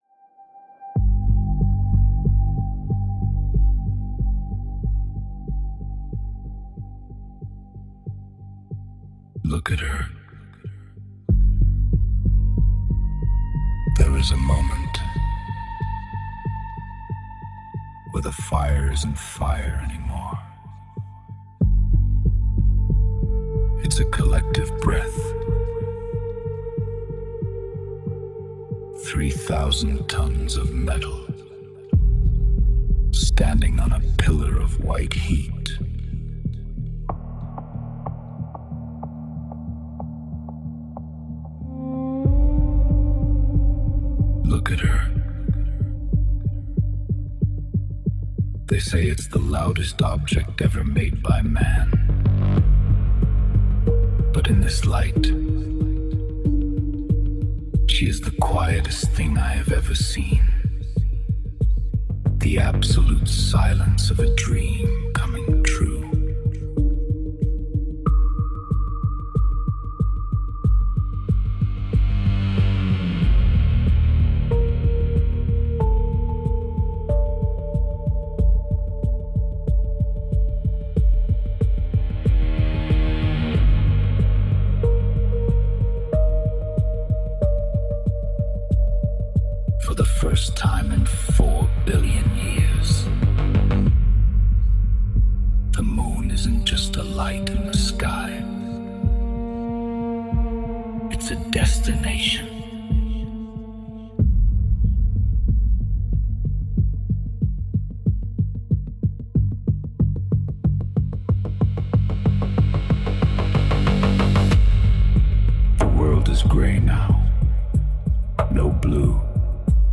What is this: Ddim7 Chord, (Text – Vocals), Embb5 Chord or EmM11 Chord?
(Text – Vocals)